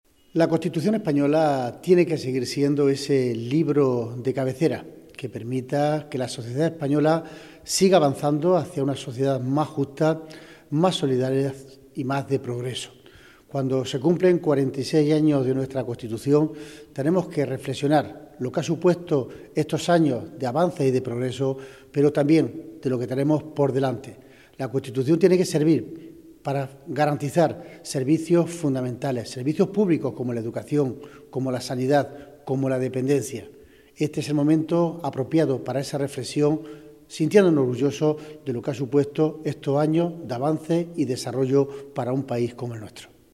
Cortes de sonido
Francisco-Reyes-Constitucion.mp3